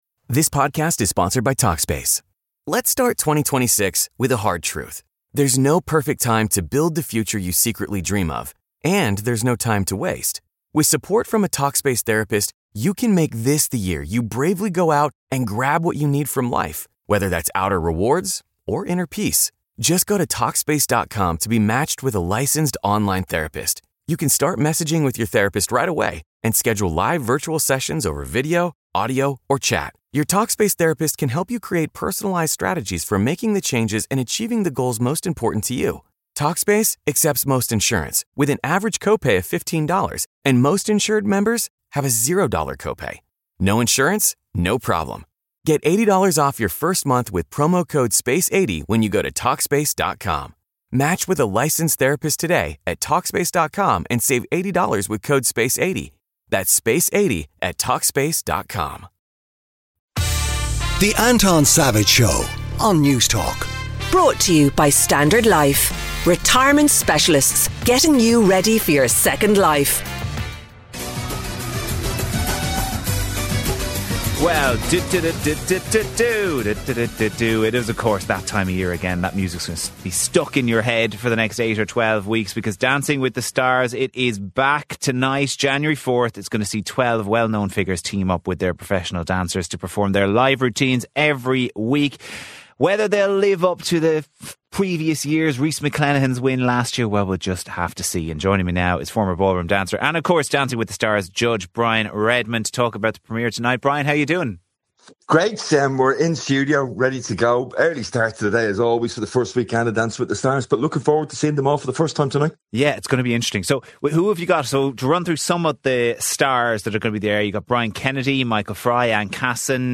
Anton Savage presents a bright, lively and engaging current affairs magazine show to kickstart your weekend.